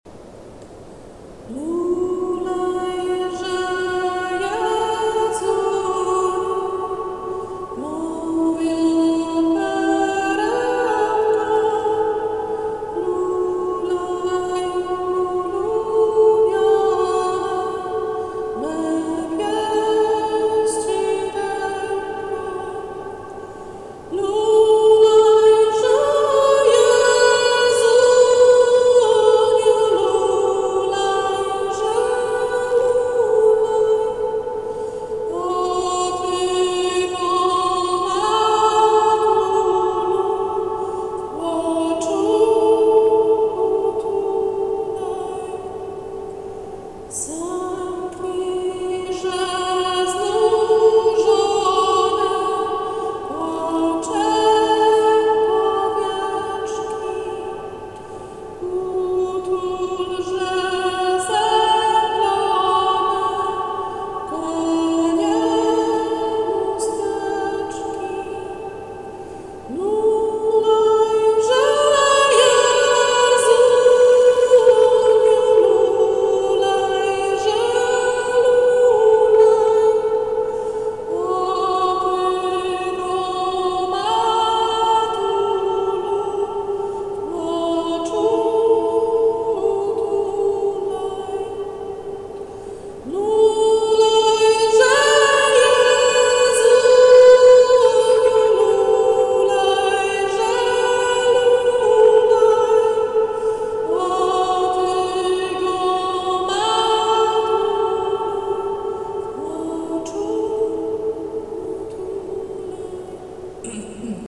Kolęda Lulajże Jezuniu – zaśpiewałam, niestety jakość jest fatalna i fałszuje, nie udało mi się też wykasować chrząkania na koniec utworku 😀 ale niech se będzie ….Udostępniam, bo  jest w niej zawarta fajna energia.